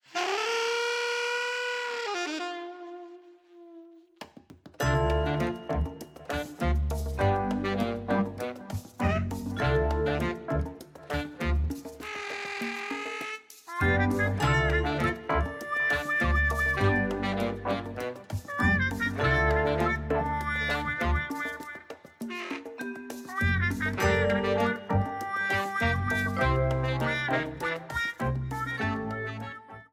A blue streamer theme
Ripped from the game
clipped to 30 seconds and applied fade-out